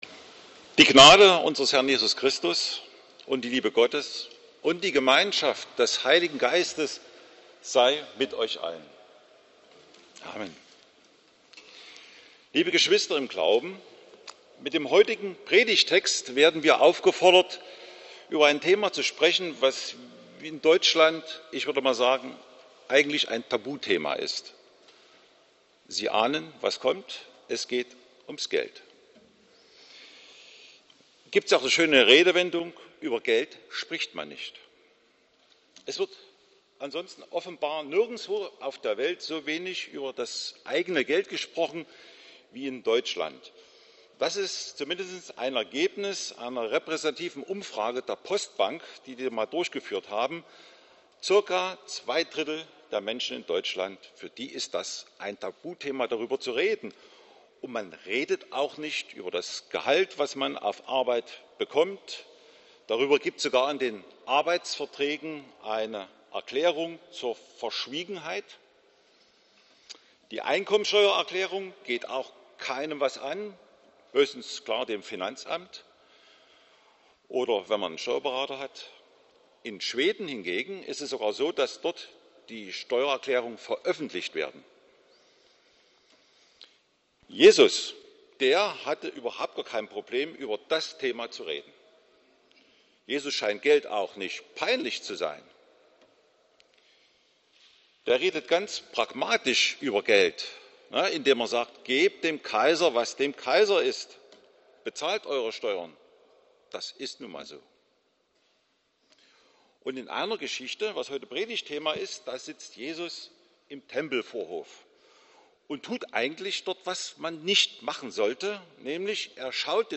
Hören Sie hier die Predigt zu Markus 12,41